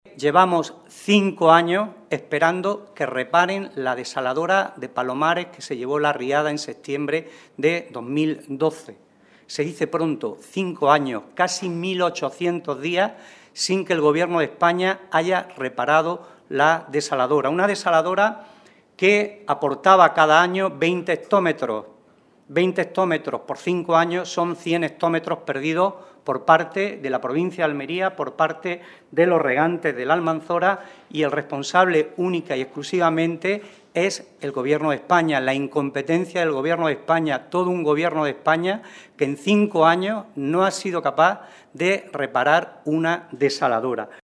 Rueda de prensa del secretario general del PSOE de Almería, José Luis Sánchez Teruel